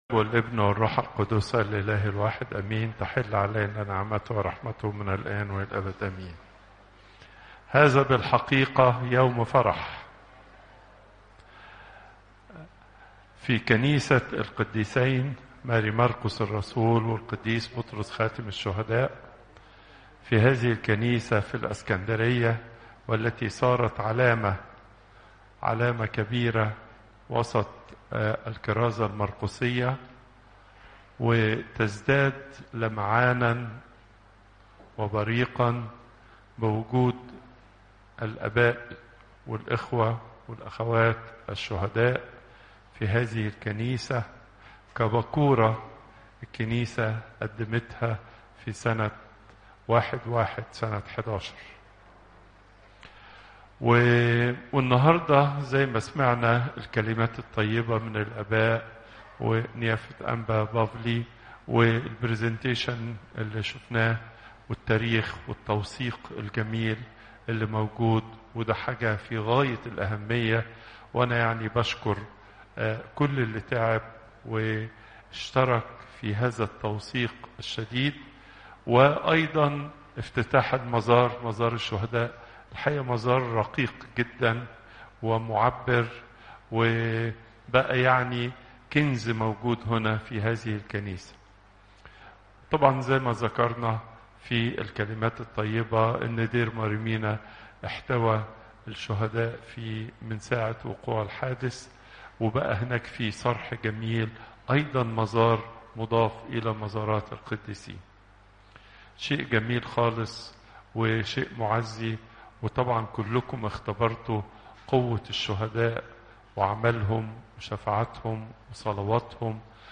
Popup Player Download Audio Pope Twadros II Wednesday, 09 July 2025 36:06 Pope Tawdroes II Weekly Lecture Hits: 135